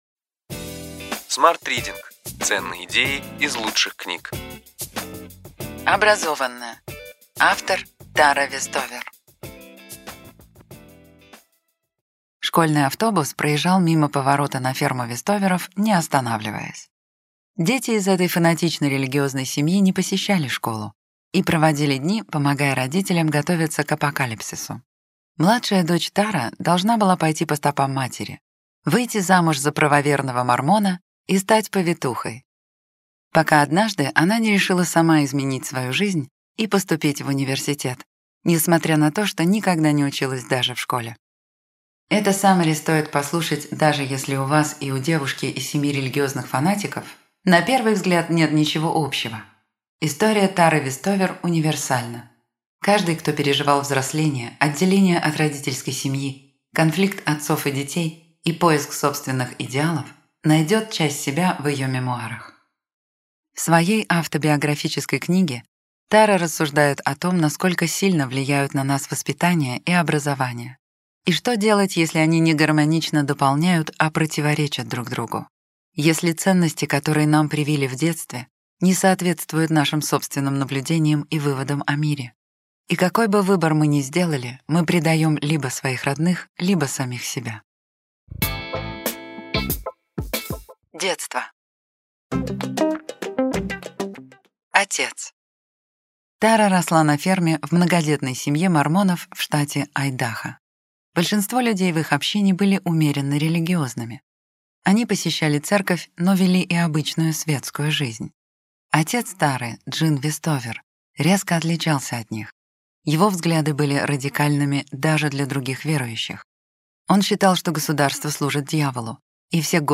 Аудиокнига Ключевые идеи книги: Образованная. Тара Вестовер | Библиотека аудиокниг